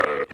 Goat Hit.wav